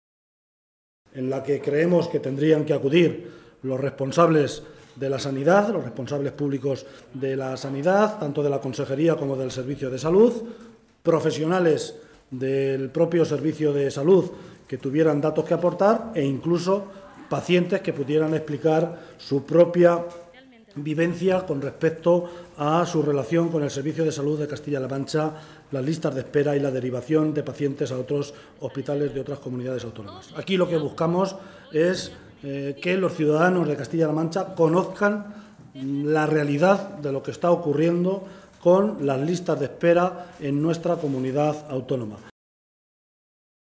Unos minutos después de dejar registrada esa iniciativa, era el propio portavoz socialista en la Cámara autonómica, José Luis Martínez Guijarro, el que comparecía ante los medios de comunicación para explicar los motivos que habían llevado a los socialistas a exigir esta comisión de investigación y los objetivos que persigue la misma.